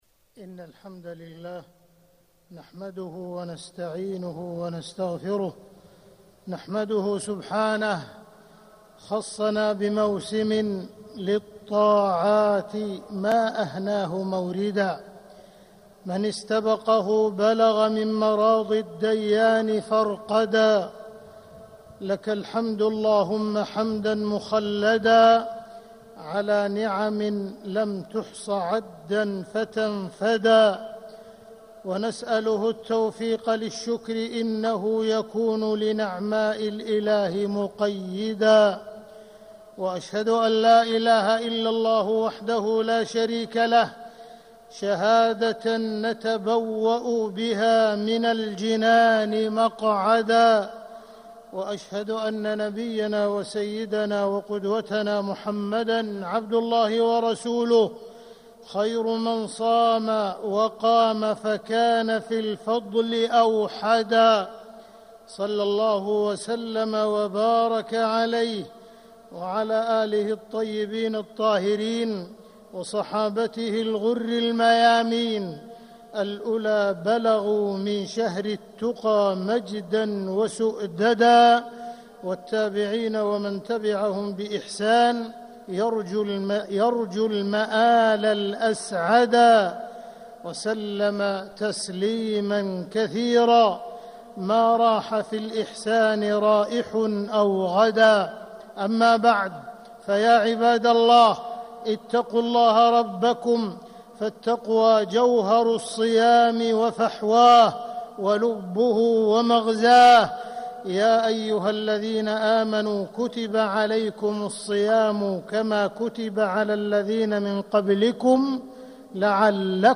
مكة: رمضان فرصة وقد انتصف - عبد الرحمن بن عبدالعزيز السديس - طريق الإسلام
مكة: رمضان فرصة وقد انتصف - عبد الرحمن بن عبدالعزيز السديس (صوت - جودة عالية